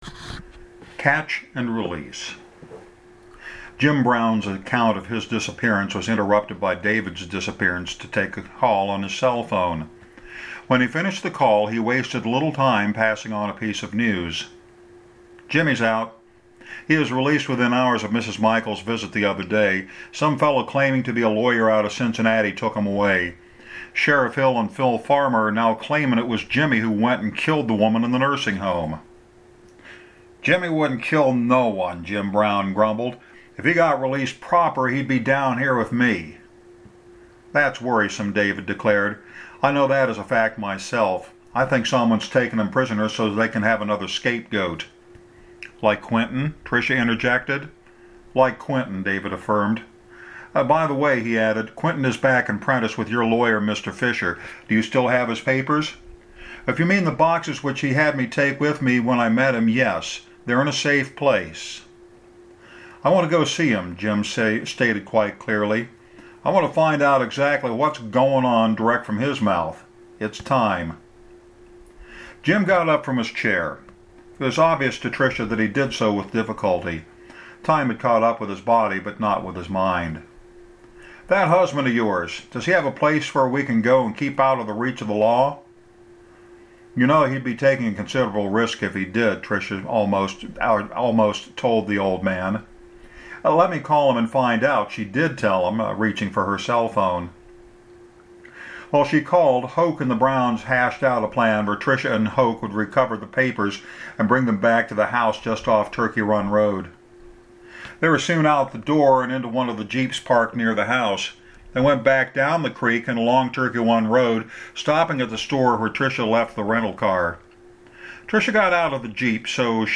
These chapters I’ve read over the past couple of months are, admittedly, imperfect.